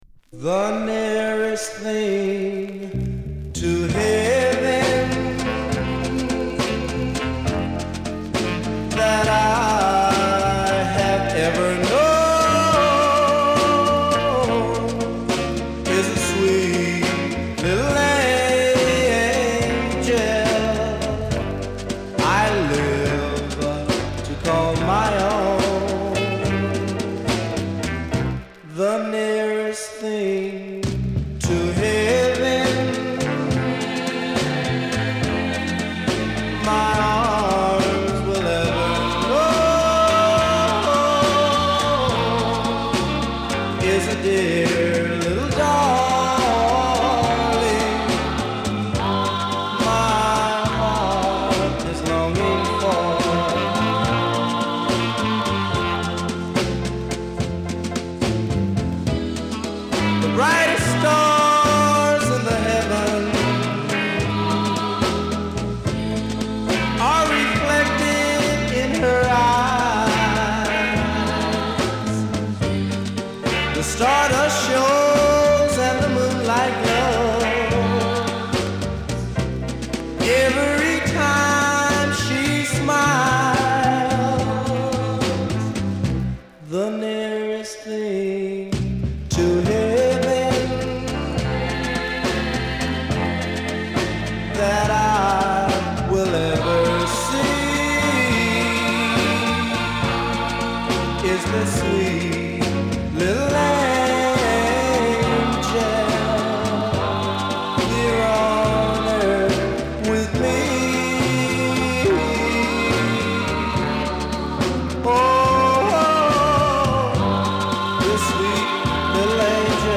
LAスタイル直系のノヴェルティ色濃いファストなR&Bグループ・ロッカー。
陽気でガヤガヤした雰囲気が演奏からダイレクトに伝わってきて気持ちいい。